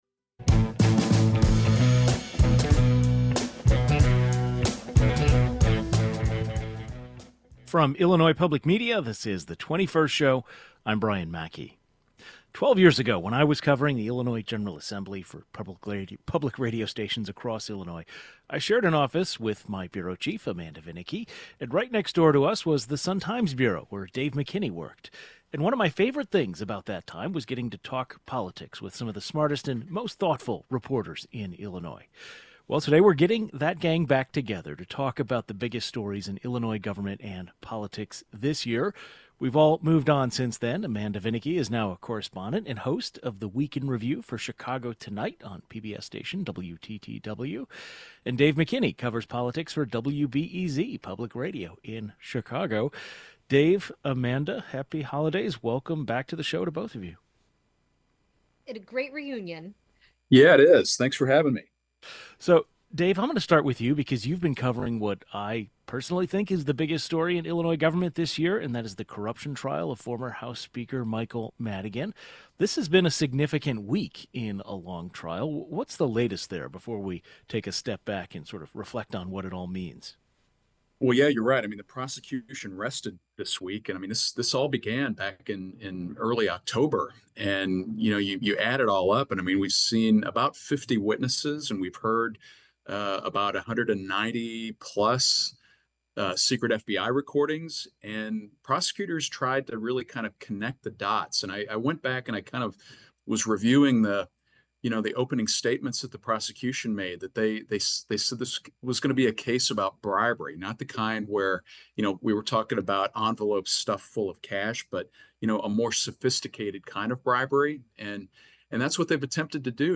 Veteran state politics reporters